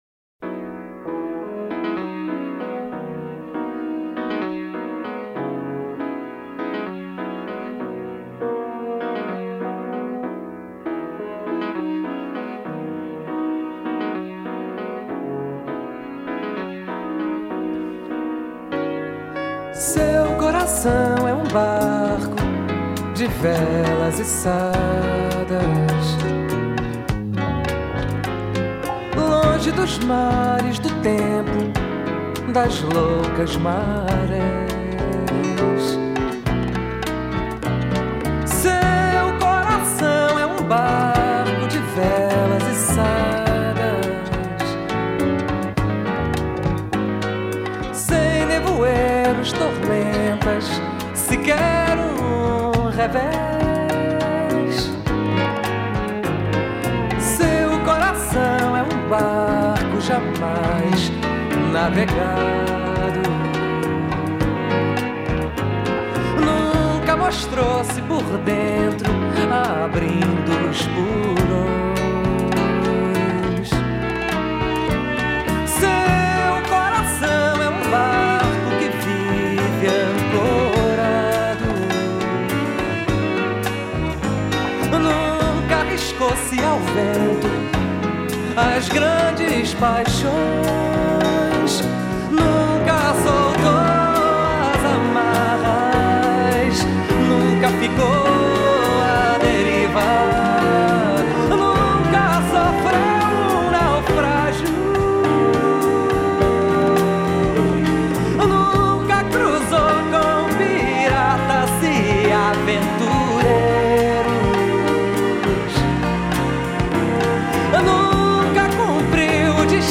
jazz pianist